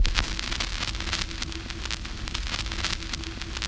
13 SCRATCHIN.wav